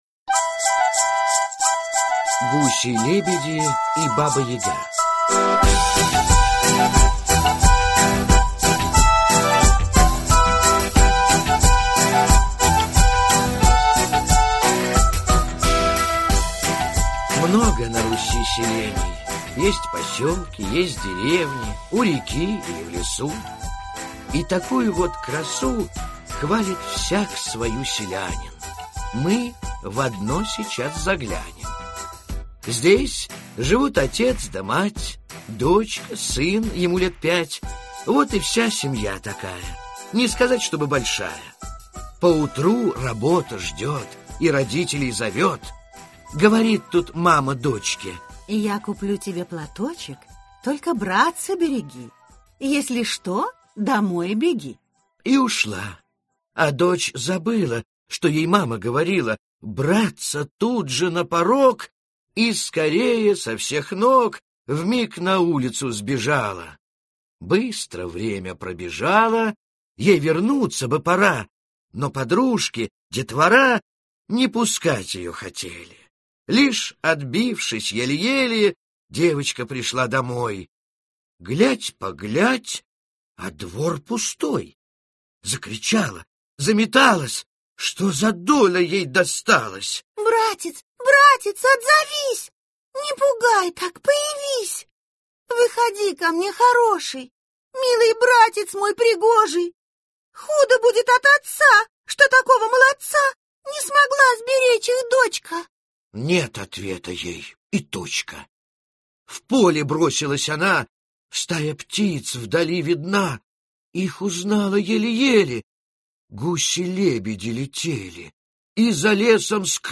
Аудиокнига Старые добрые сказки в стихах Диск 2 | Библиотека аудиокниг